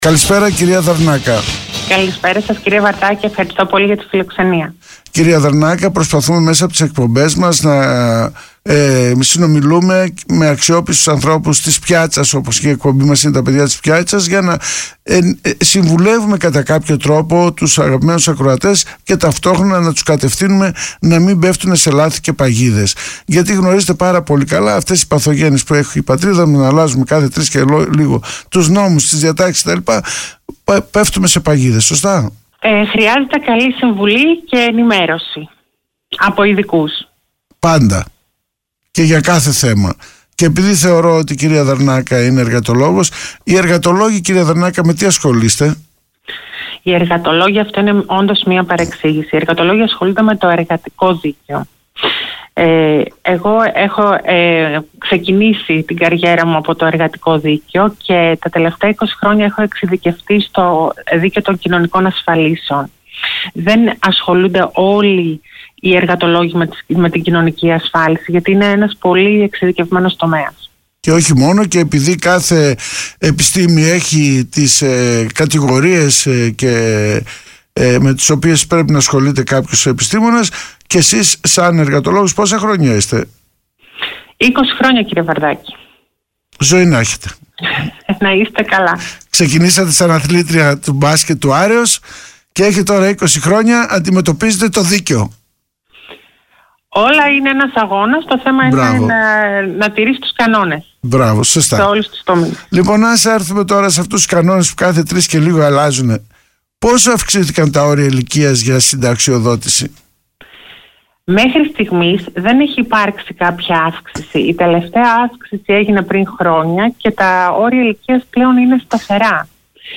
Συνέντευξη στο Metropolis 95,5fm